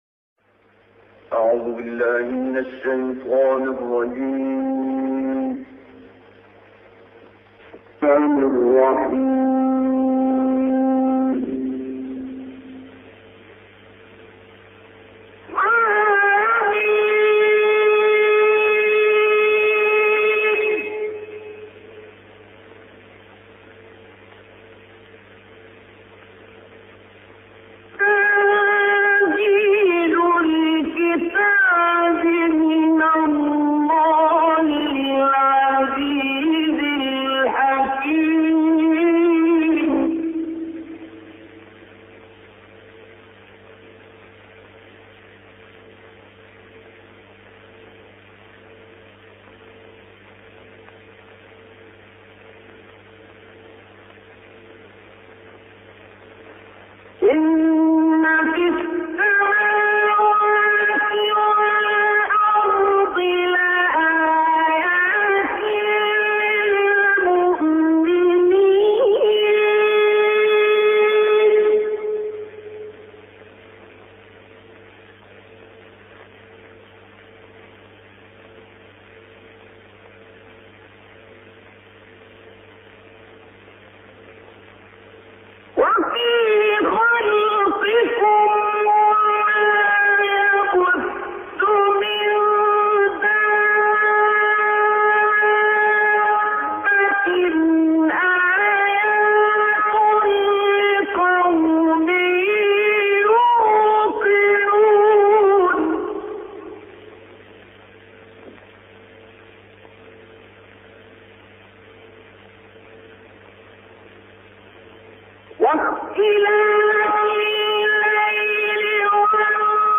تلاوت سوره جاثیه با صوت «عبدالباسط»
خبرگزاری بین‌المللی قرآن(ایکنا) به مناسب ایام نوروز سال 1396 تلاوت آیات 1 تا 11سوره مبارکه جاثیه با صوت عبدالباسط محمد عبدالصمد، قاری برجسته مصری را ارائه می‌کند.
این تلاوت که در اوایل دهه 60 میلادی در کشور لیبی اجرا شده در کانال تلگرامی صدای بهشت منتشر شده و مدت زمان آن 7 دقیقه است.